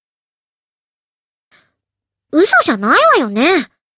failsound.ogg